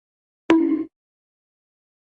Play Cat Head Bonk Sound - SoundBoardGuy
cat-head-bonk-sound.mp3